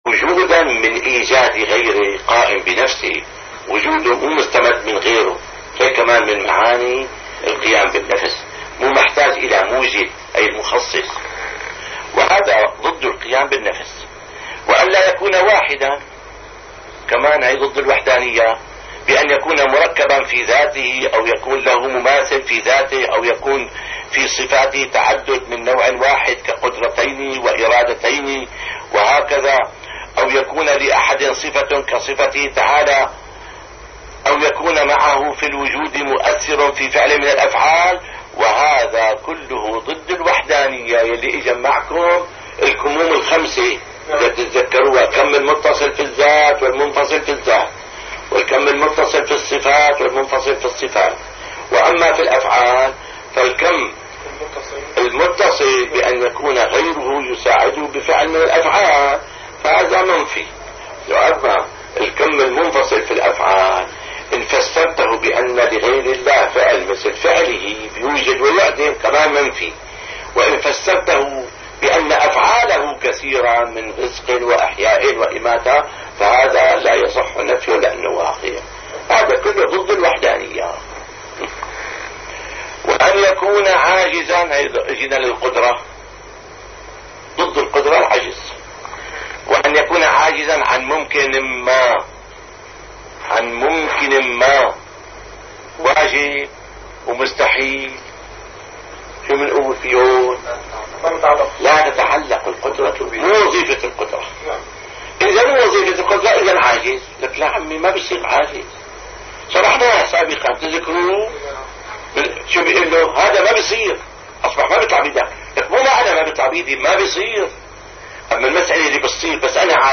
- الدروس العلمية - شرح جوهرة التوحيد - الدرس الواحد والعشرون